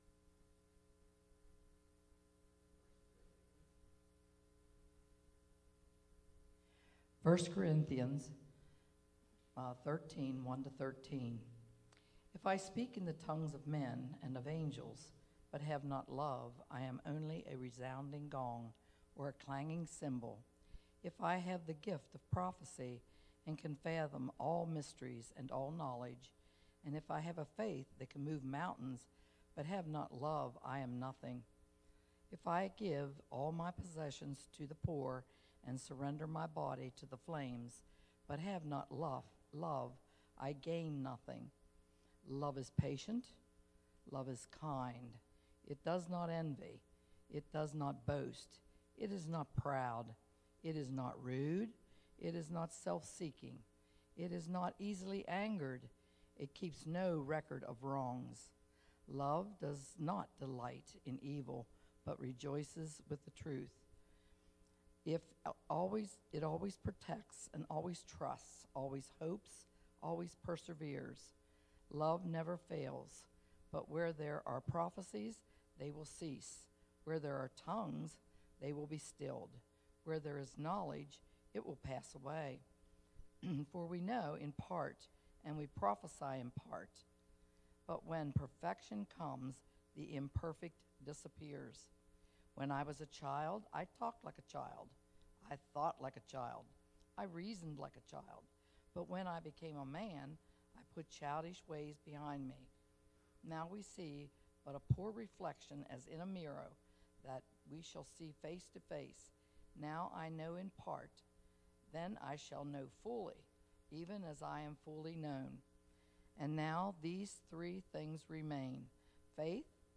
Sermons Archives - Page 8 of 10 - Marion Mennonite Church